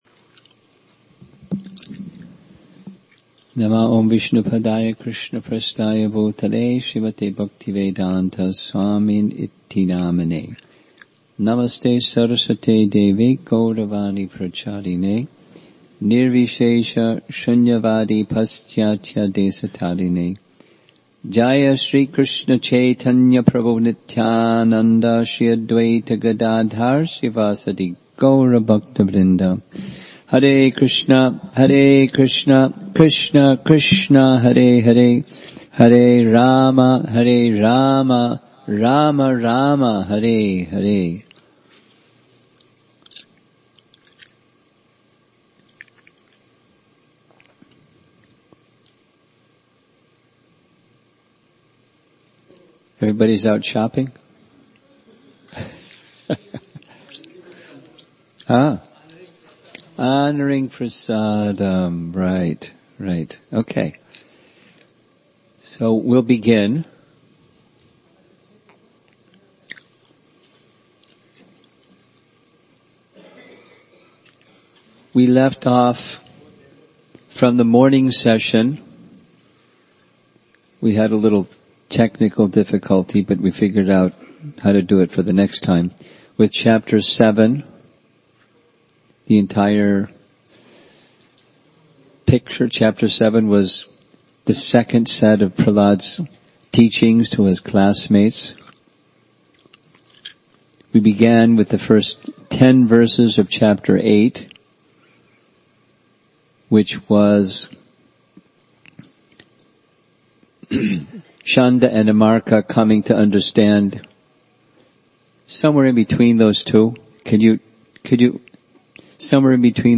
Session 10 Thanksgiving Day Retreat Central New Jersey November 2018